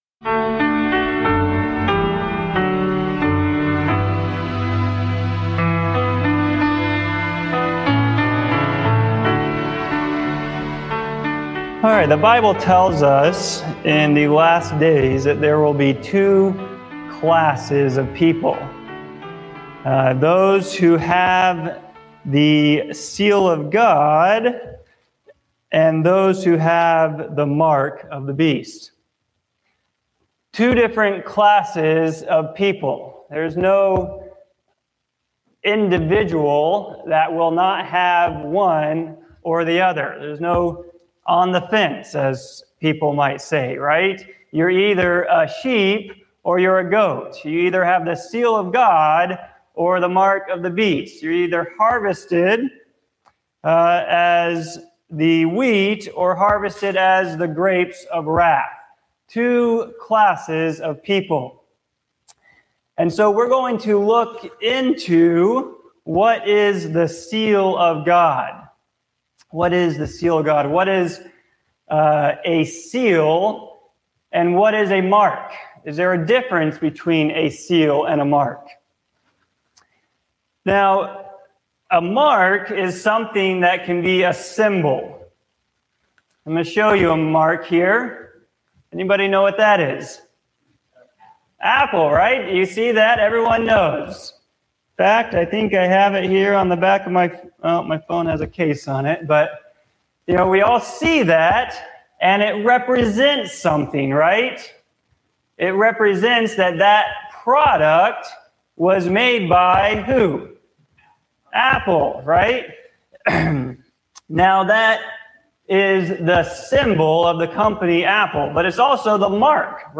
This sermon explores the biblical concept of the seal of God, contrasting it with the mark of the beast, and explaining their spiritual significance, symbolism, and application in the life of believers.